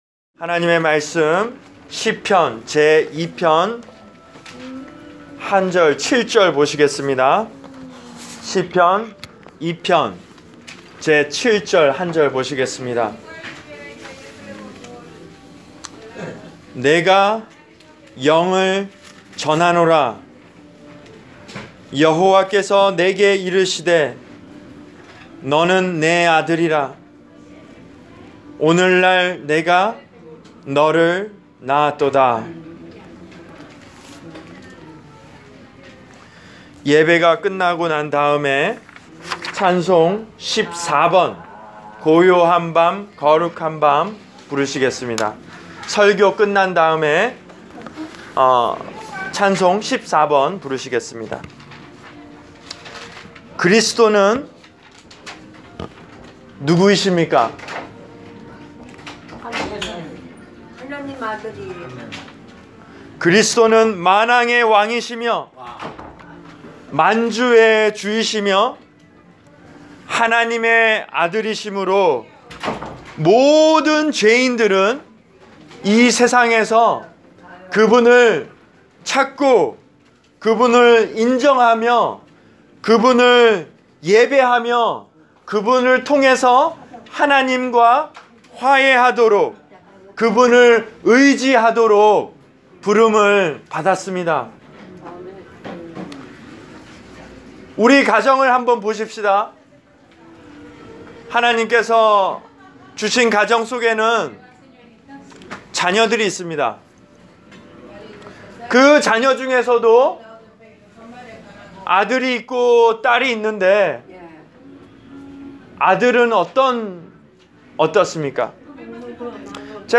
Preached for: Hudson View Rehab Center, North Bergen, N.J.